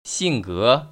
[xìnggé] 싱거